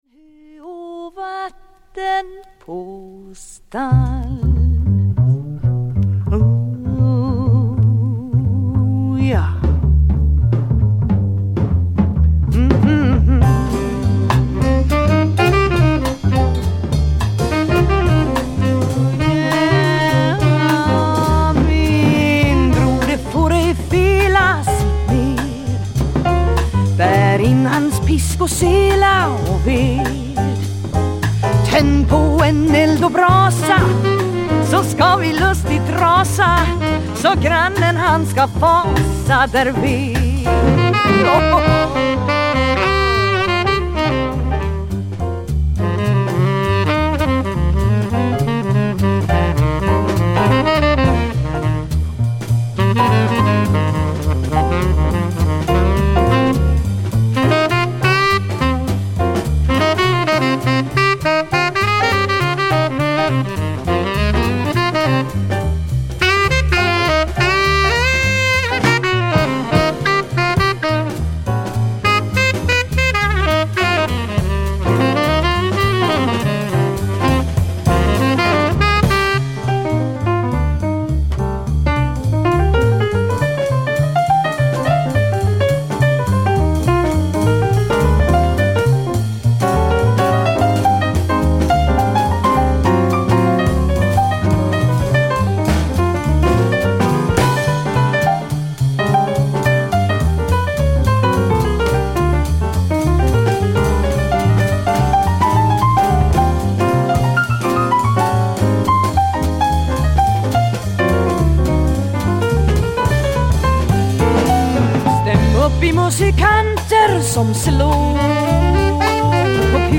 Jazz Vocal sweden